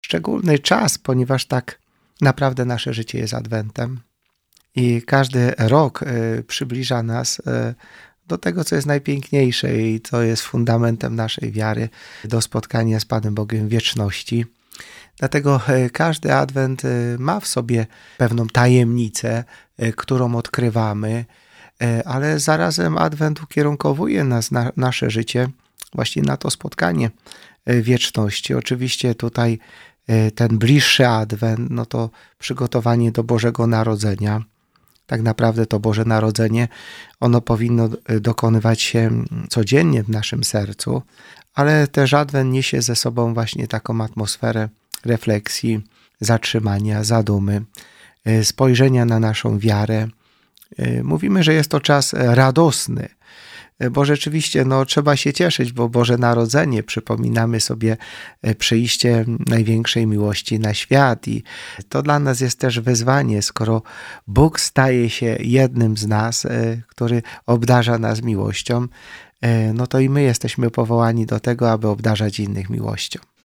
I w jaki sposób Jezus przyszedłby na świat w XXI w.? -pytamy ojca biskupa Jacka Kicińskiego.
– Całe nasze życie jest Adwentem – mówi ojciec biskup Jacek Kicińśki.